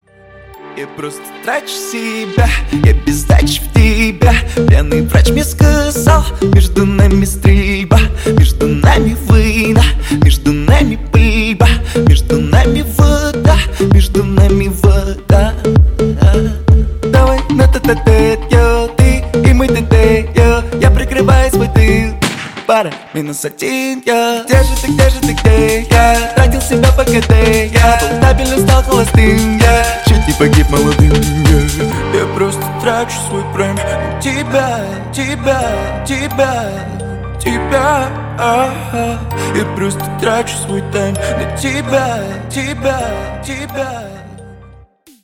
• Качество: 128, Stereo
поп
ритмичные
веселые